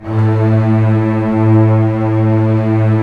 Index of /90_sSampleCDs/Roland L-CD702/VOL-1/STR_Cbs Arco/STR_Cbs2 Orchest